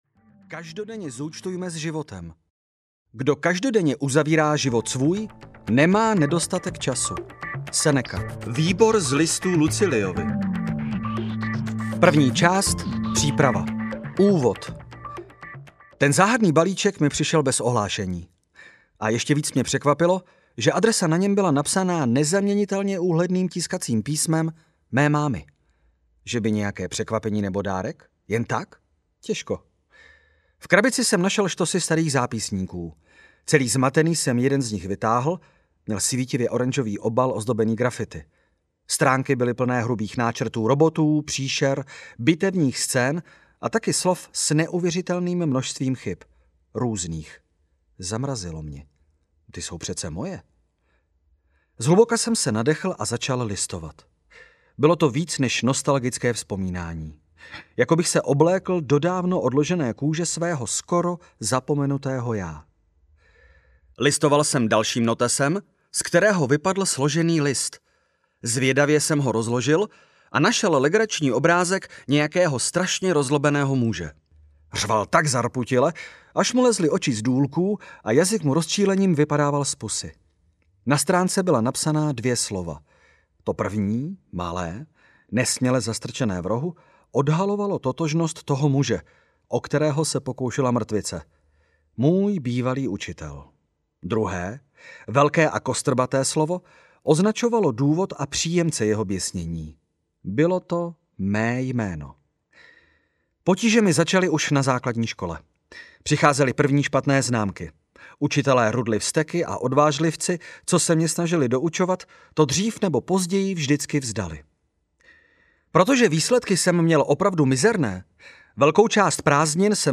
Metoda Bullet Journal audiokniha
Ukázka z knihy